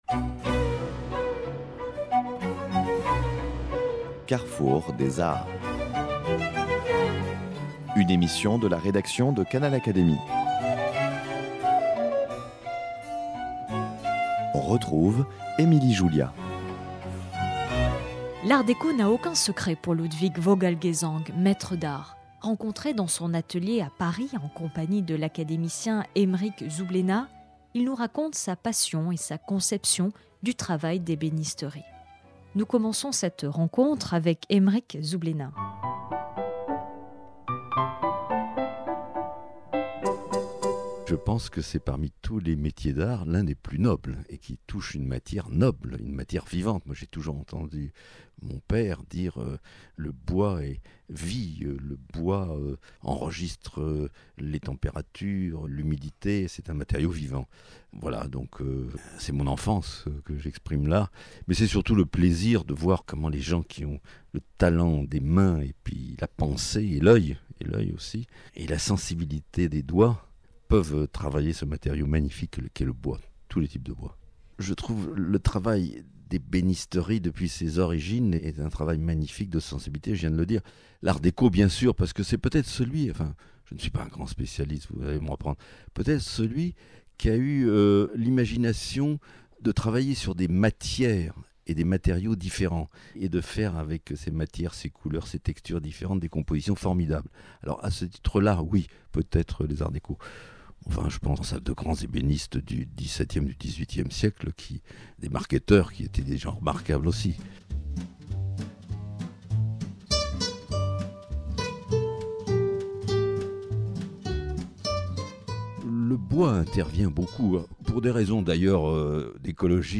Rencontré dans son atelier à Paris